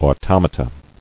[au·tom·a·ta]